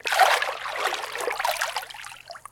Splash_water.ogg